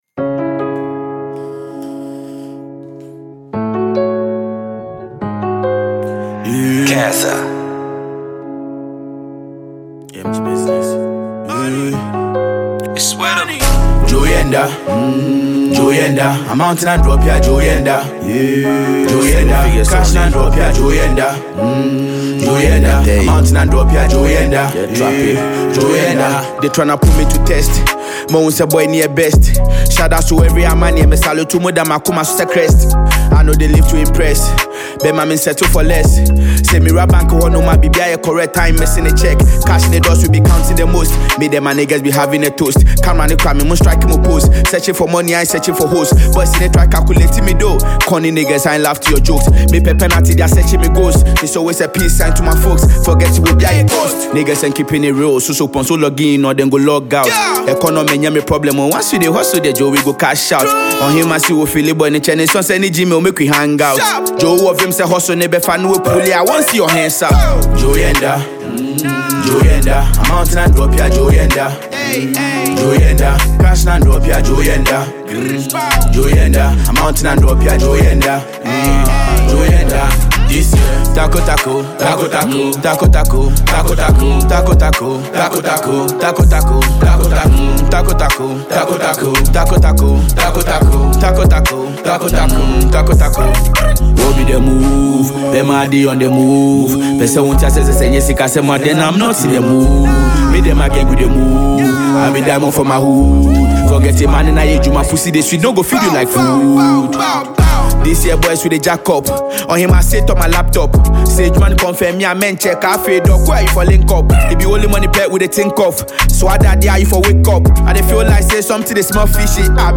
Ghana Music
hot drill jam